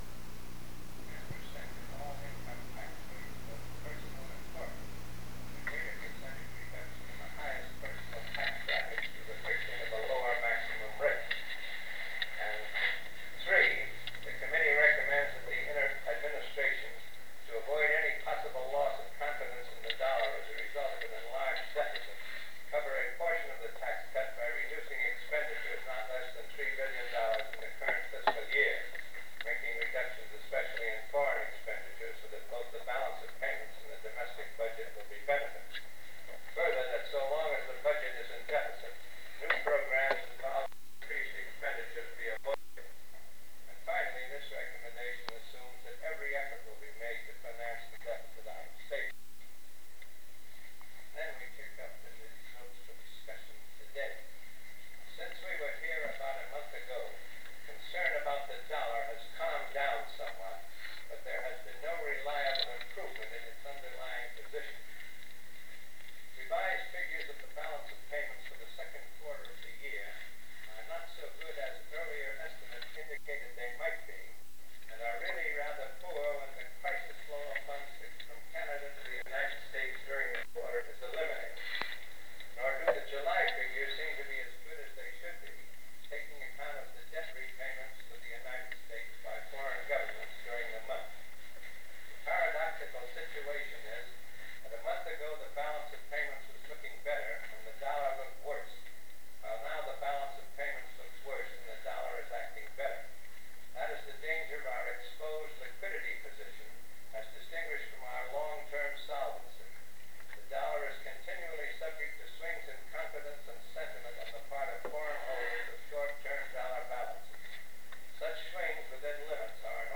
Secret White House Tapes | John F. Kennedy Presidency Meeting with Business Leaders on the Tax Cut Proposal Rewind 10 seconds Play/Pause Fast-forward 10 seconds 0:00 Download audio Previous Meetings: Tape 121/A57.